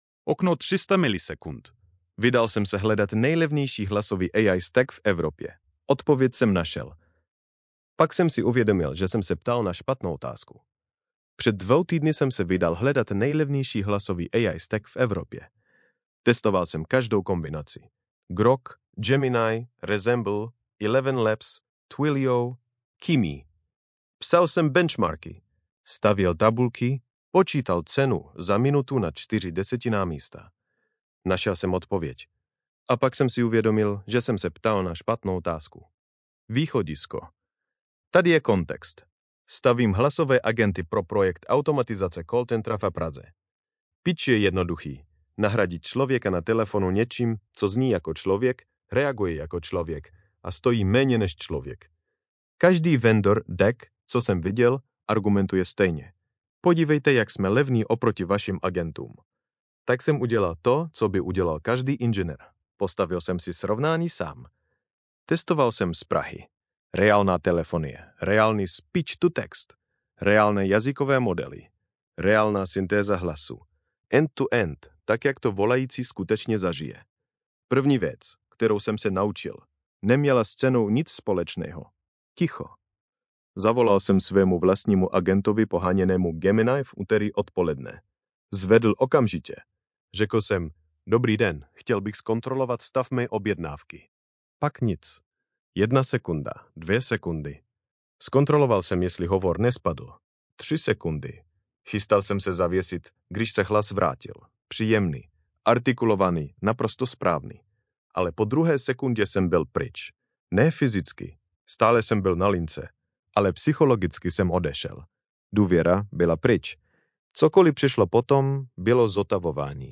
Podcastová audio verze této eseje, vytvořená pomocí Grok Voice API.